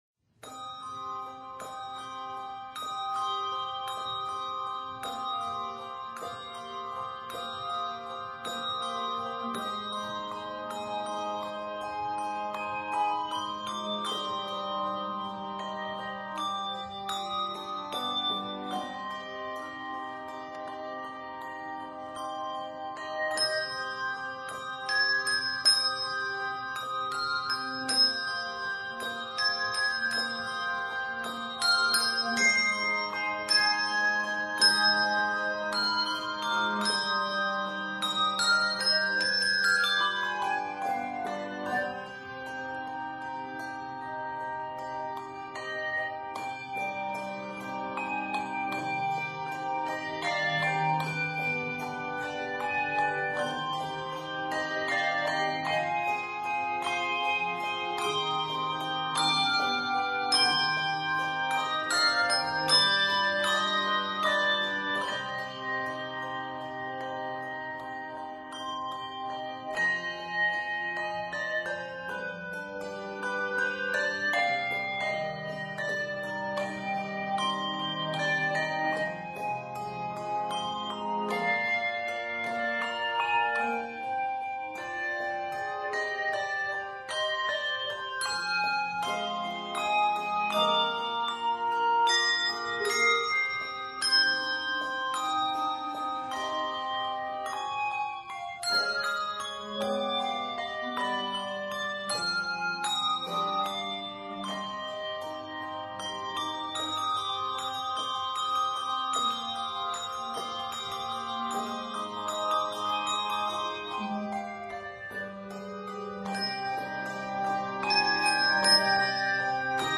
handbell music
N/A Octaves: 3-6 Level